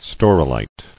[stau·ro·lite]